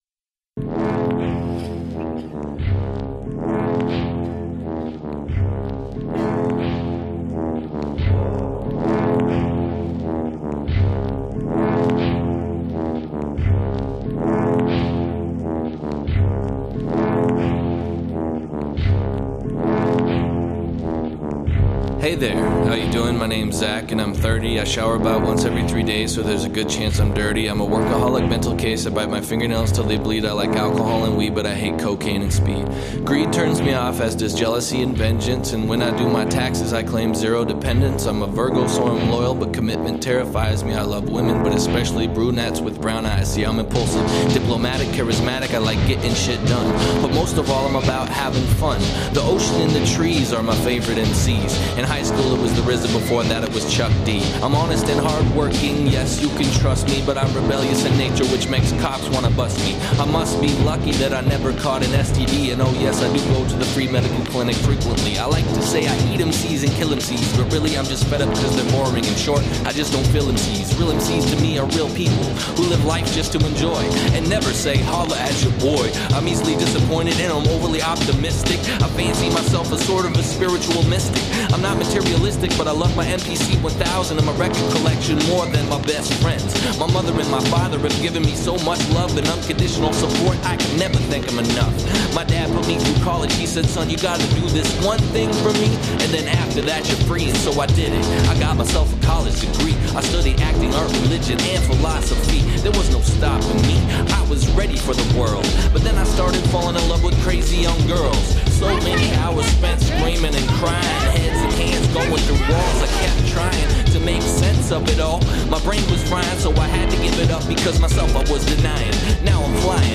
Electronic Indie